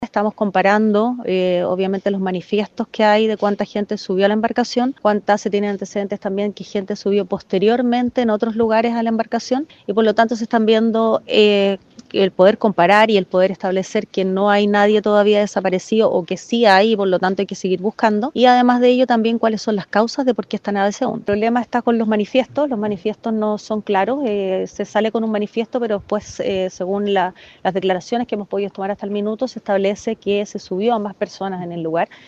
María Angélica de Miguel, fiscal jefe de Osorno, indicó que “estamos comparando, obviamente, los manifiestos que hay de cuánta gente subió a la embarcación. Se tienen antecedentes también que gente subió posteriormente en otros lugares a la embarcación”.